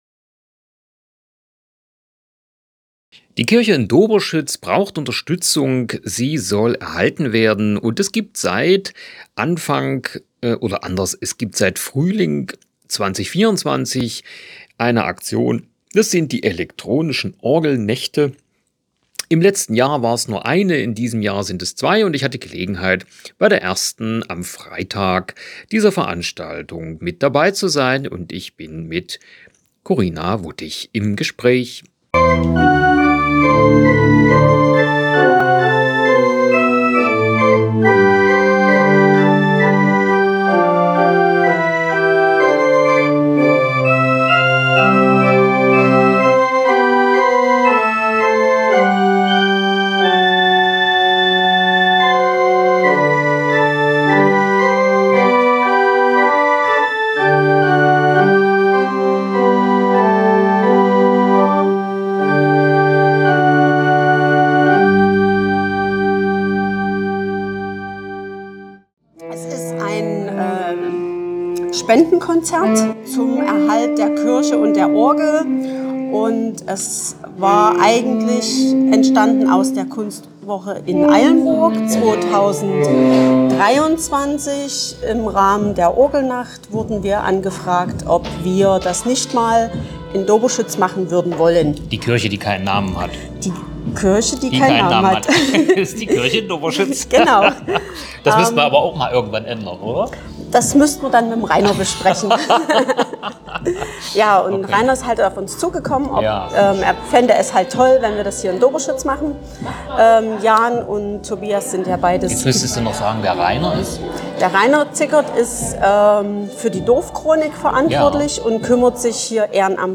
o-ton-elektronische-orgelnacht-doberschuetz-2025.mp3